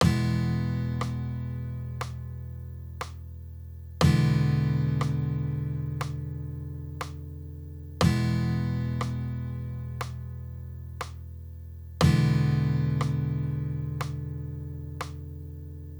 The audio samples that follow each pair will sound out the traditional chord first followed by the easy version.
E and E easy chords
emaj-easy.wav